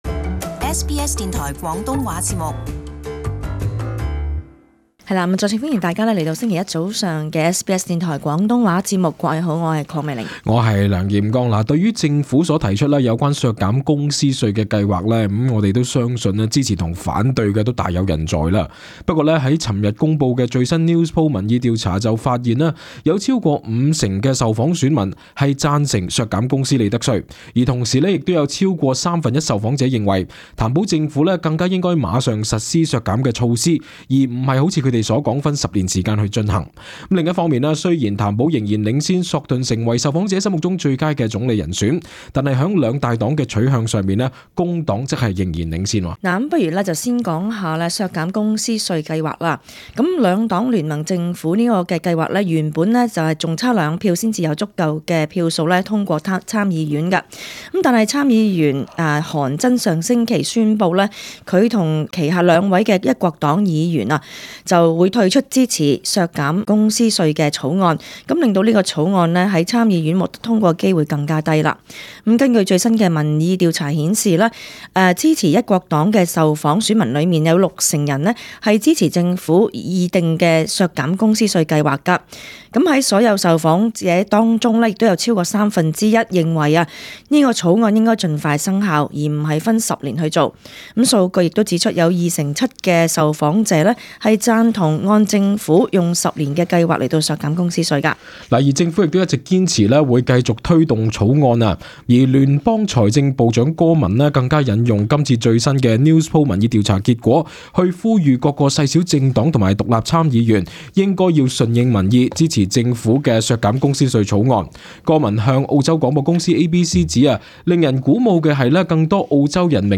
【時事報導】民調：超過五成受訪者支持削減公司稅 04:36 The latest Newspoll shows that while Labor is making strides, leader Bill Shorten is falling behind.